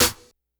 Snares
snr_54.wav